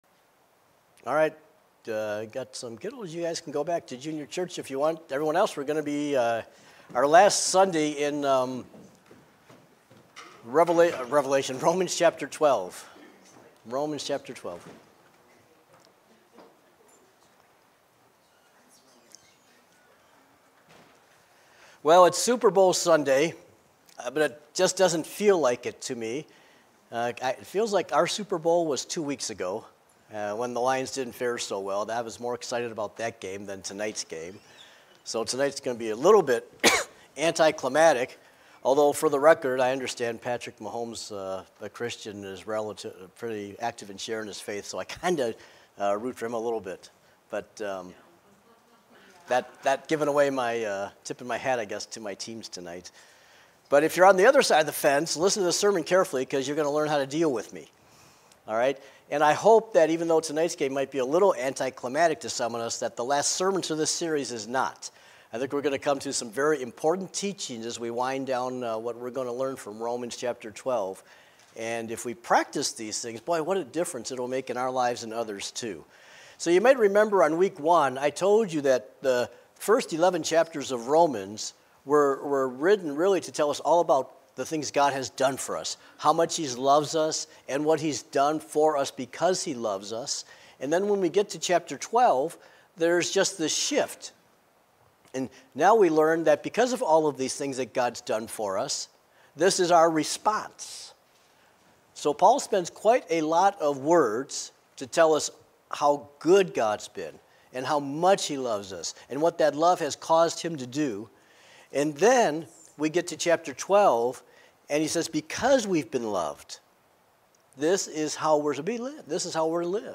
Sermons | Tri County Christian Church